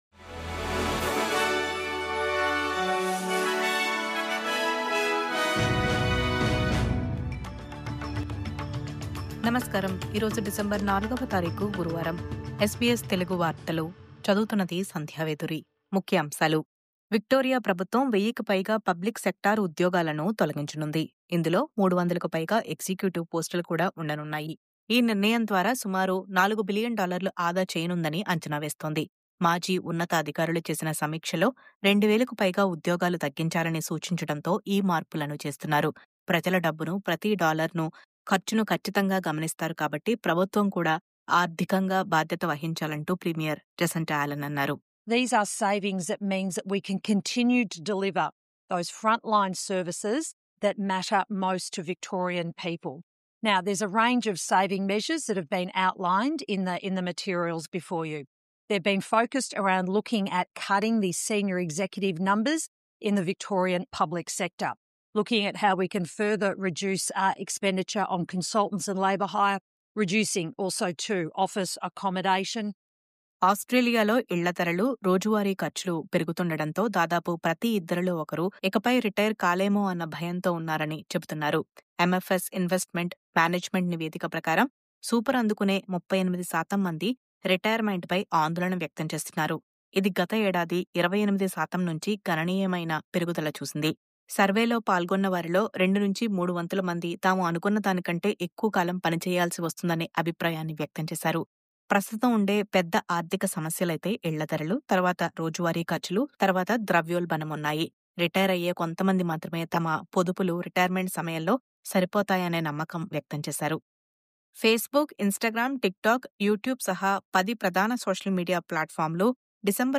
News update: విక్టోరియా ప్రభుత్వ ఉద్యోగాల్లో కోత…